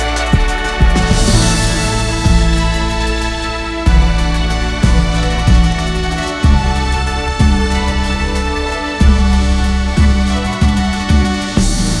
music-generation text-to-audio text-to-music
"text": "A dynamic blend of hip-hop and orchestral elements, with sweeping strings and brass, evoking the vibrant energy of the city.",